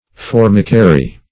Definition of formicary.
Formicary \For"mi*ca*ry\, n.